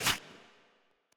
VIRTUOS_Assets/Audio/Soundeffects/SliceGame/Slice-001.wav at main